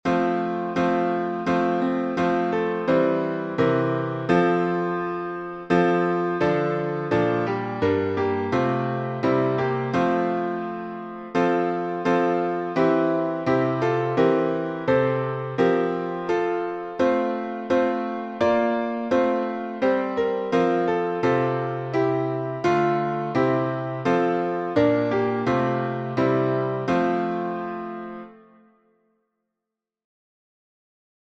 Take My Life, and Let It Be Consecrated — six stanzas in F, alternate — Hendon.